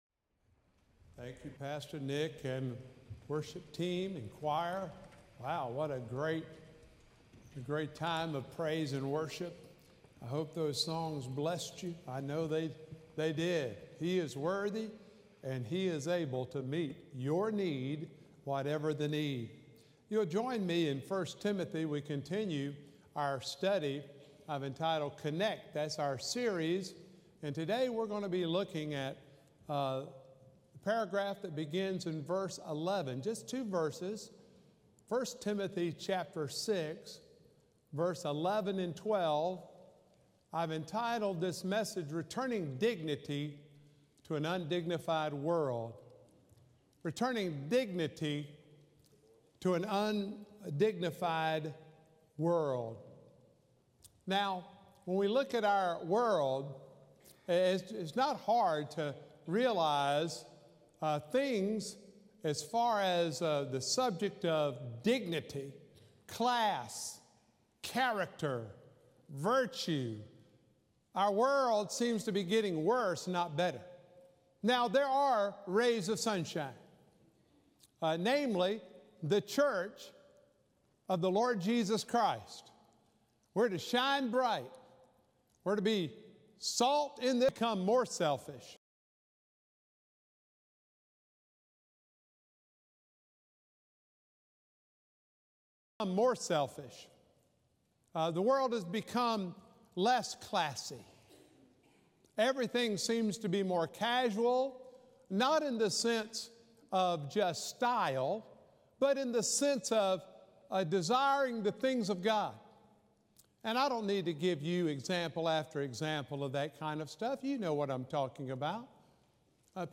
Sermons
March-5-2023-Sermon.m4a